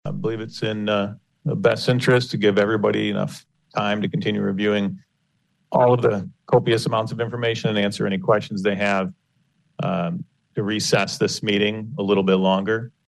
Following less than a five-minute resumption of a special meeting convened to discuss the future of the County’s Administrative Health Officer, the governing panel agreed to “pause” the proceedings for another week. Board Chairman Joe Moss (R-Hudsonville), who had asked for this meeting earlier this month against Adeline Hambley, stated why he wanted a continuance.